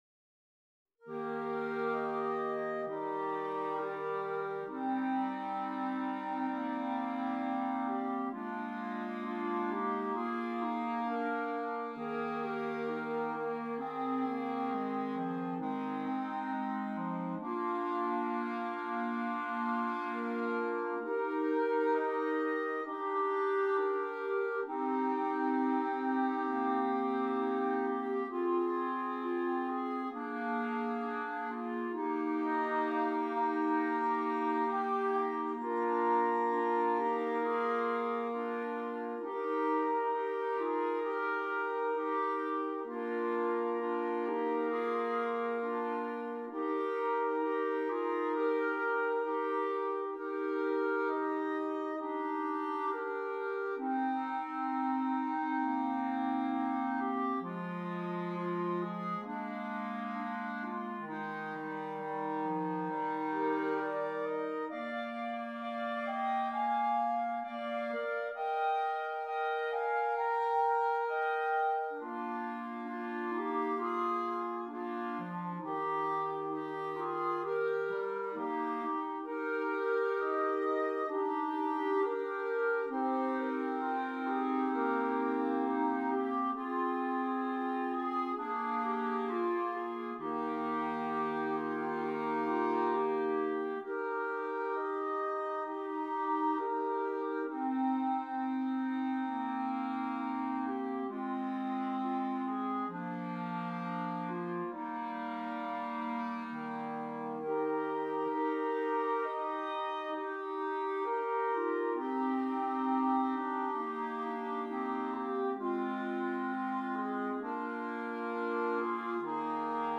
5 Clarinets
Traditional Austrian Carol